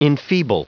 Prononciation du mot enfeeble en anglais (fichier audio)
Prononciation du mot : enfeeble